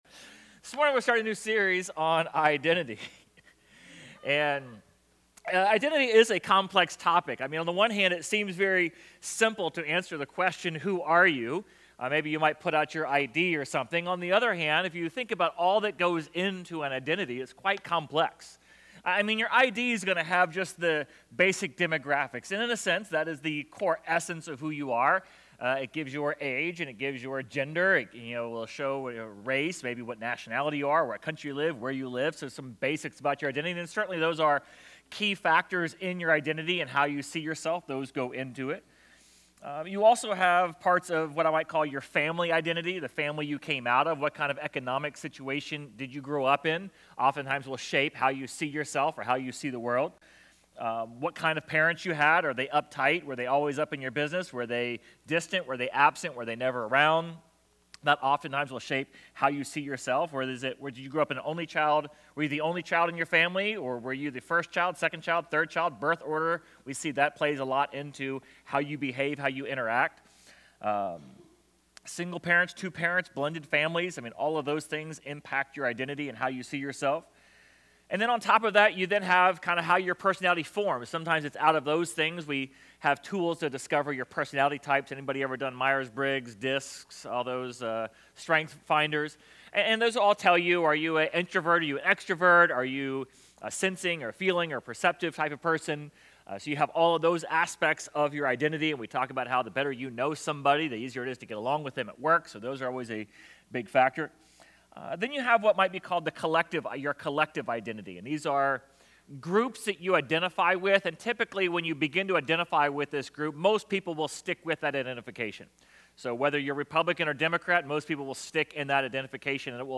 Sermon Audio/Video | Essential Church
Sermon_7.6.25.mp3